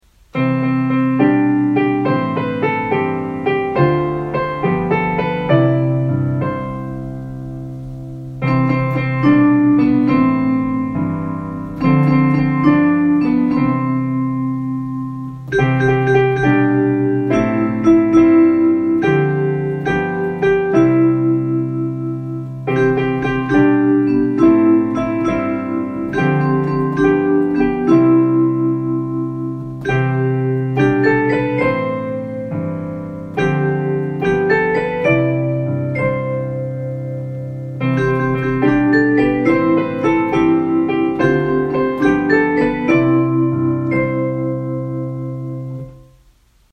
Children's Song